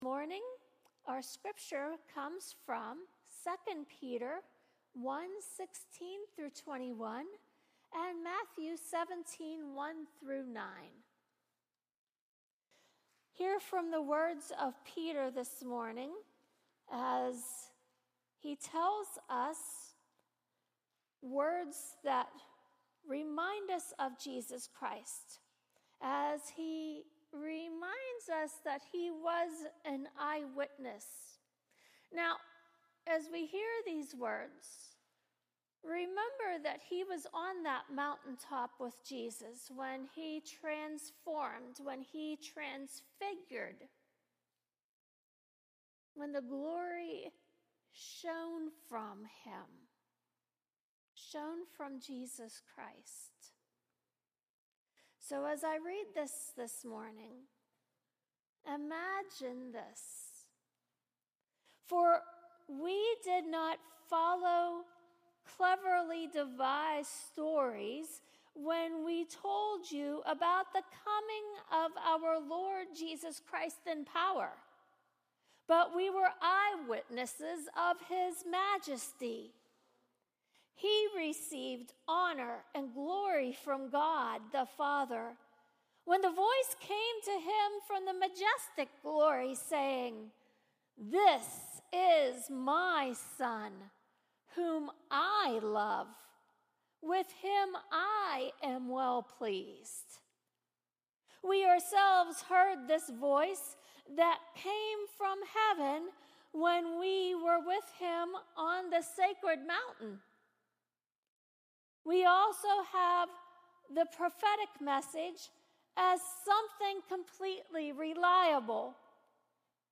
Sermons | Stone UMC